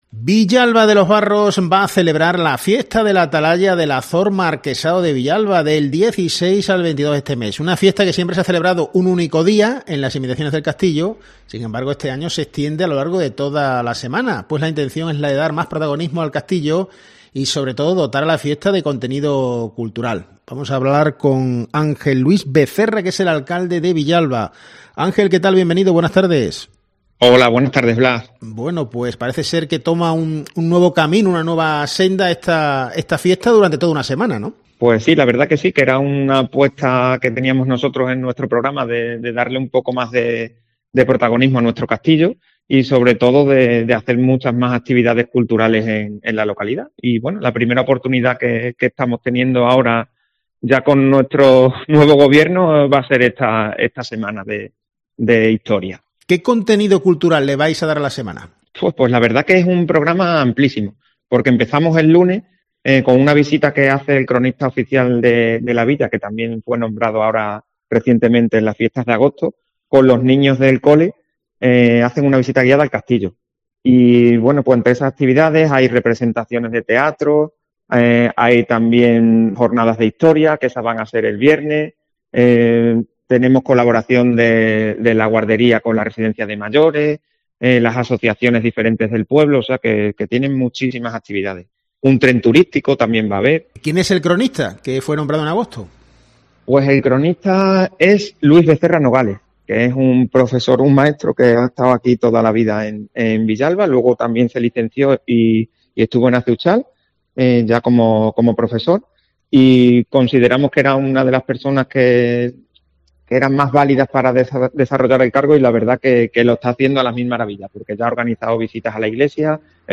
En COPE, hemos hablado con Ángel Luis Becerra, alcalde de Villalba.